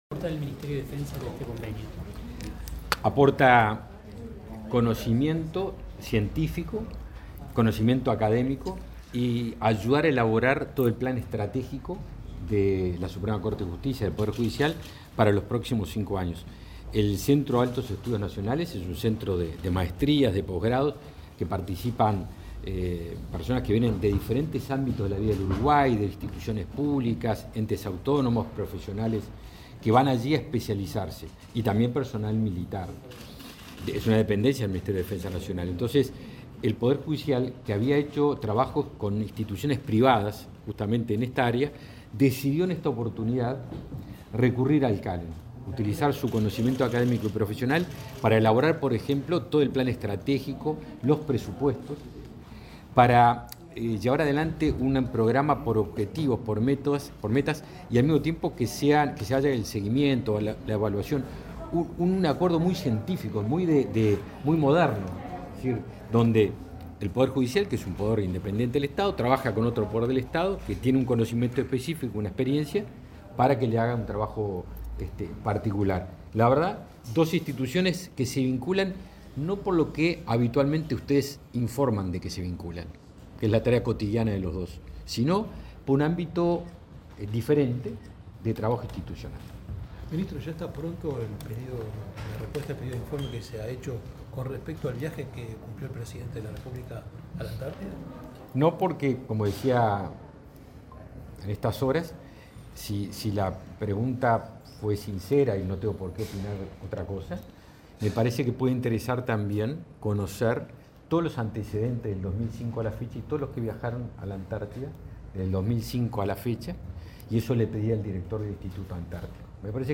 Declaraciones del ministro de Defensa Nacional, Javier García
Luego García dialogó con la prensa.